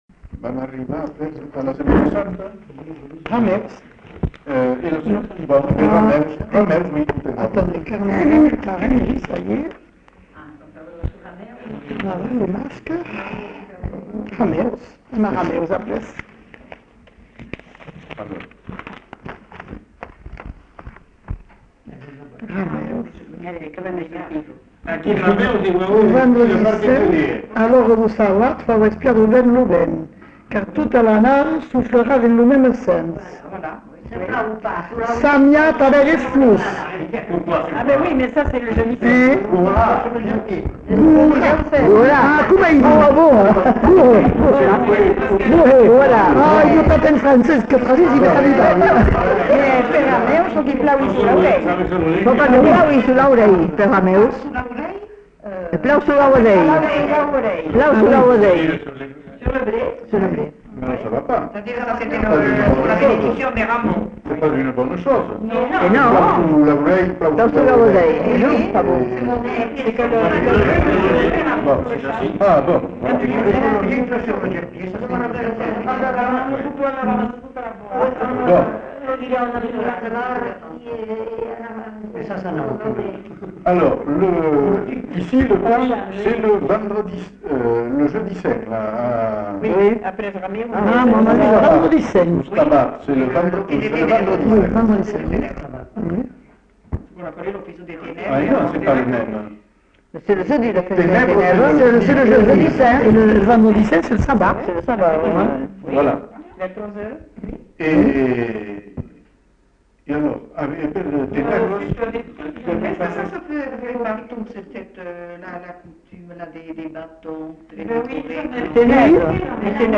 Lieu : Uzeste
Genre : témoignage thématique